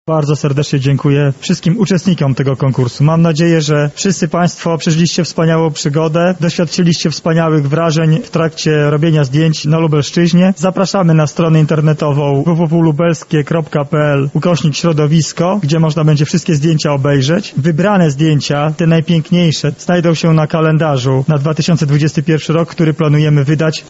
-mówi Sebastian Trojak, członek Zarządu Województwa Lubelskiego oraz przewodniczący komisji konkursowej.